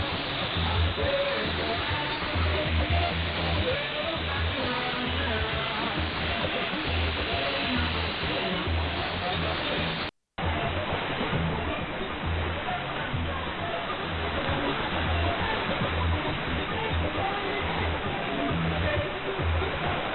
Below are a few audio comparisons between the KiwiSDR and the Perseus SDR.
Second 0 - 10> Perseus SDR
Second 10 - 20> KiwiSDR
Radio-Clube-du Para-4885KHz-Kiwi-Perseus.mp3